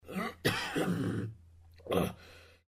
cough3.mp3